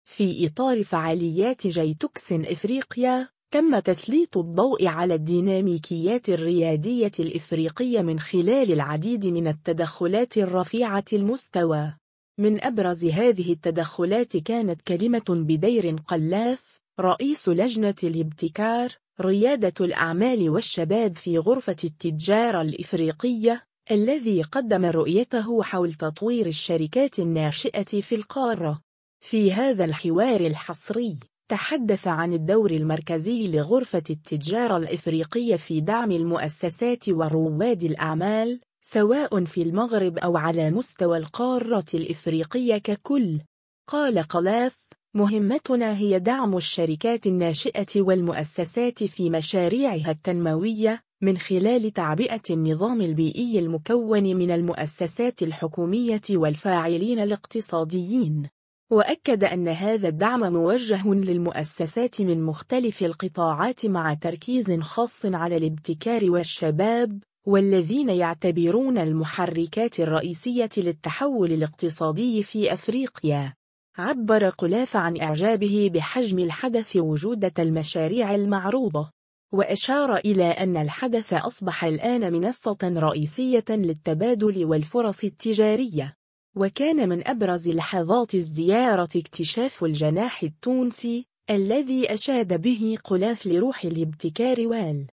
حوار حصري: غرفة التجارة الأفريقية تنبهر بالإنجازات المبتكرة للشركات الناشئة التونسية [فيديو]